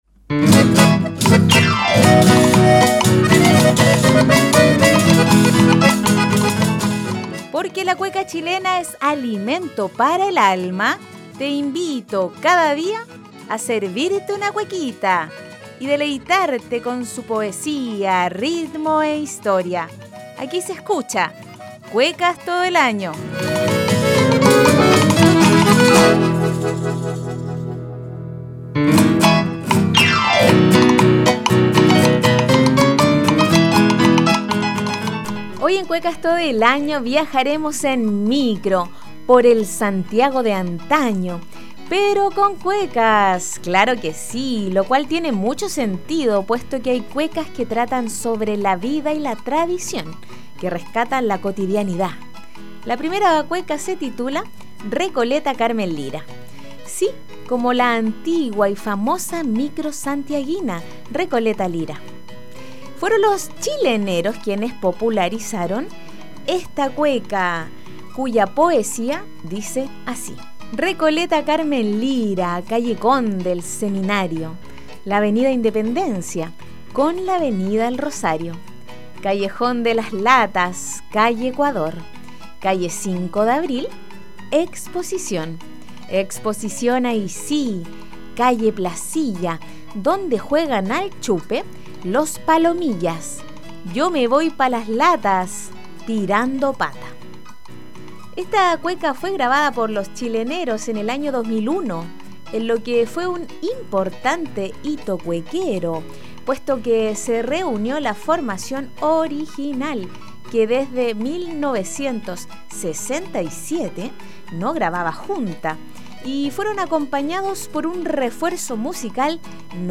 En el programa 10 de la primera temporada de “Cuecas todo el año”, nos deleitamos con cuecas que nos llevan a viajar en micro por el Santiago de antaño: “Recoleta, Carmen, Lira” y “Cuando chico en una liebre” también conocida con el nombre de “Tírame pa`Franklin”. Además de apreciar su poesía y conocer un poco más de su contexto de creación, las escucharemos en las versiones de Los Chileneros y Los Corrigüela.